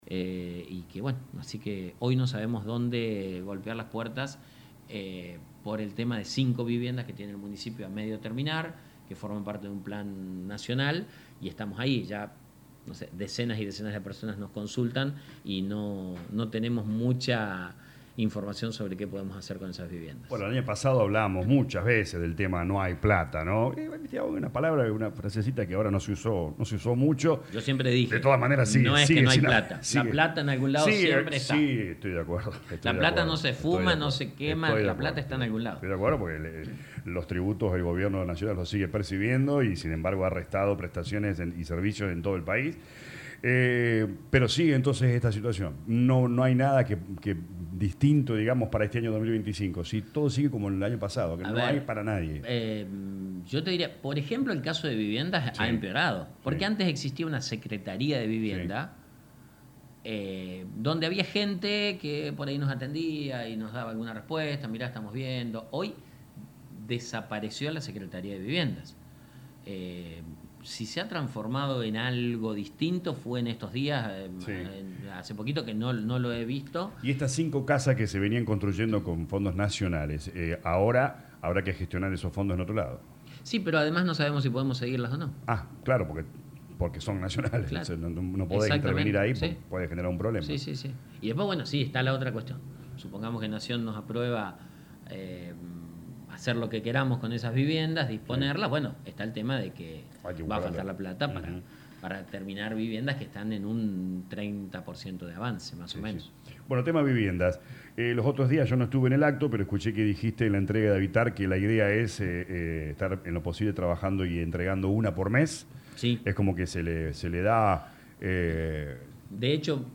VIDEO COMPLETO DE LA ENTREVISTA